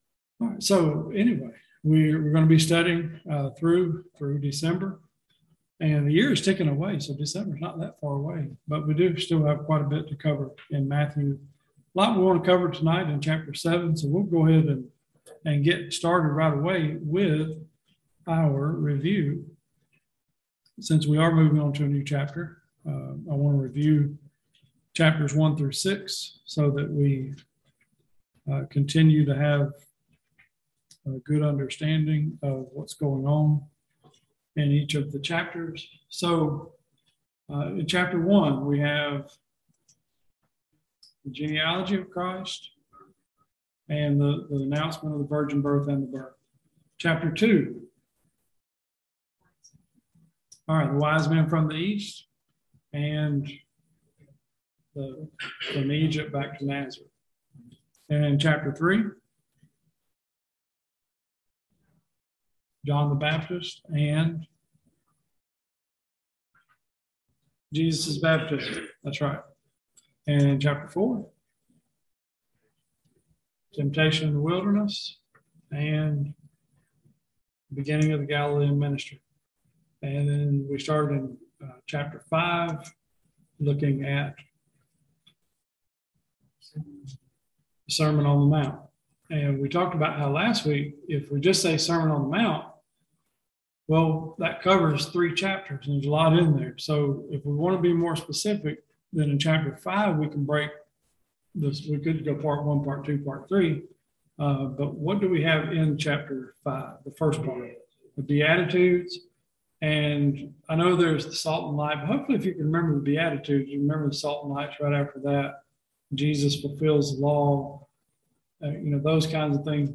Service Type: Bible Classes